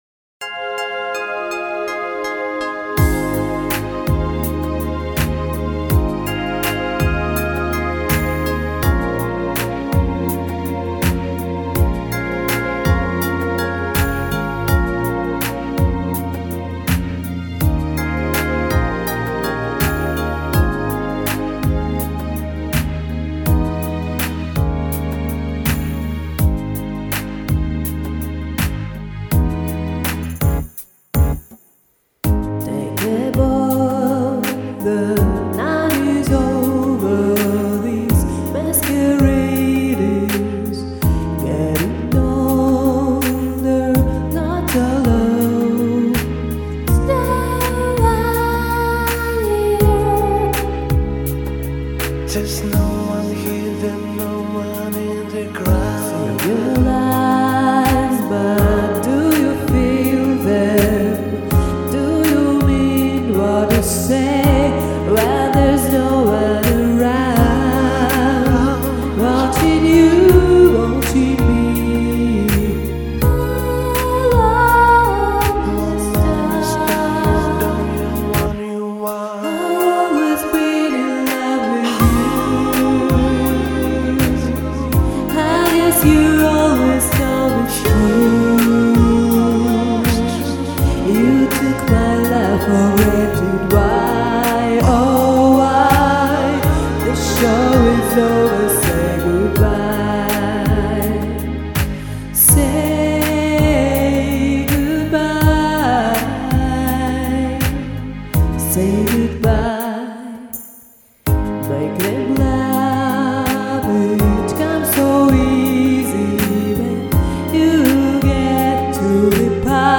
ну а девочные все мои голоса)